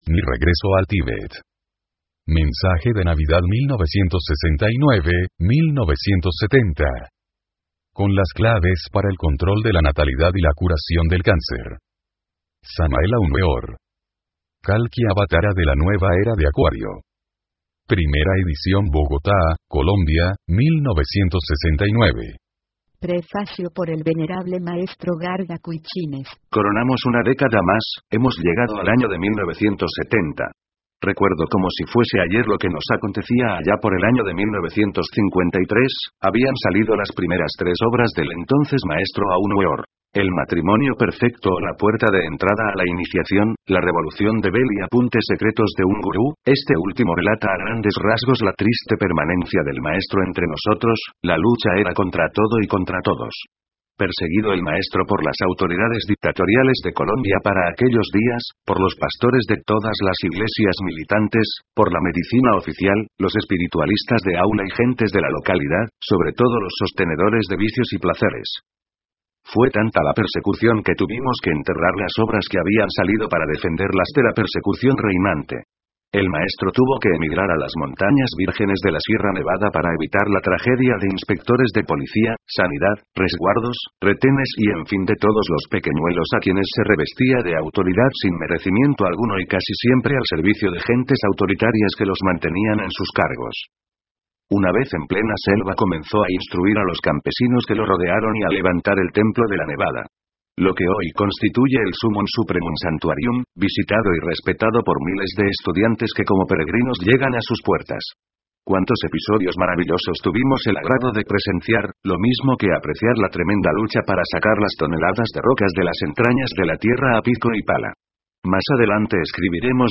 Audiolibros del maestro Samael Aun Weor